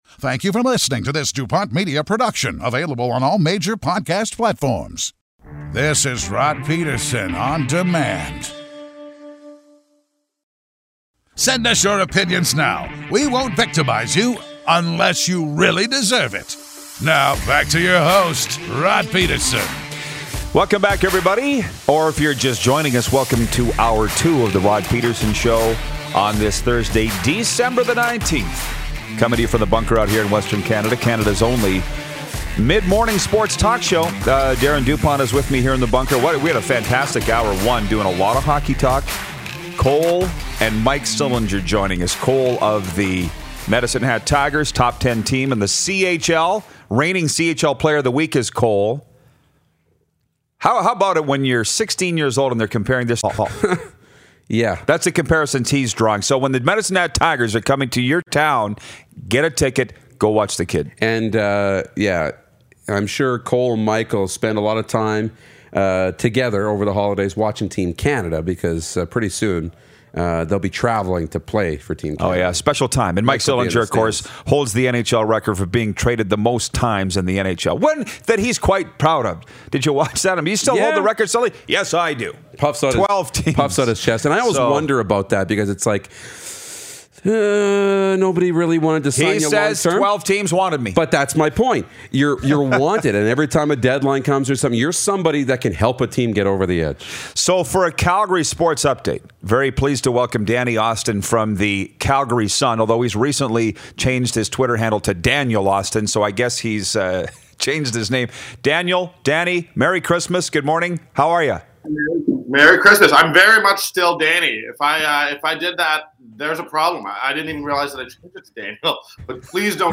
It’s beginning to look a lot like sports talk!
RP Show Curling Correspondent, Kirk Muyres joins us! Skip from 2017 World Champion Team Homan, Rachel Homan gives us a call!